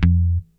F2 4 F.BASS.wav